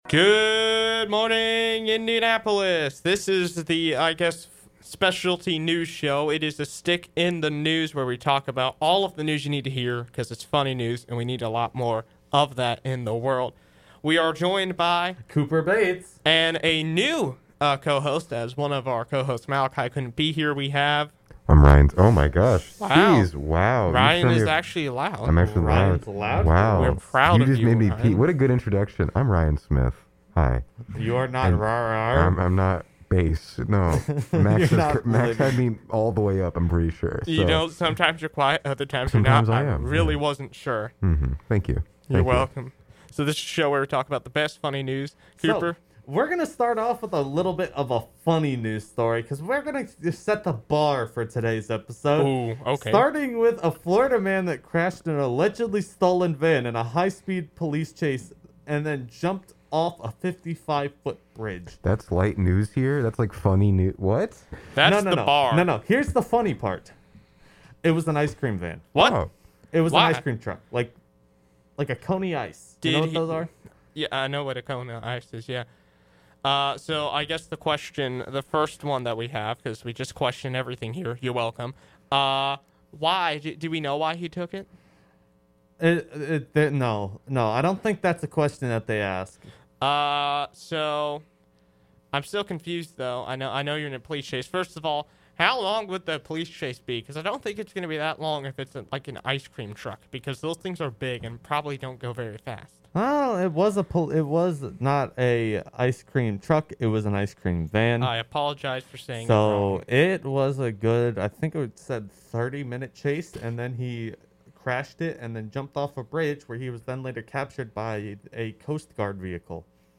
Airs live every Wednesday from 9:15-10:00 AM on Giant 90.9.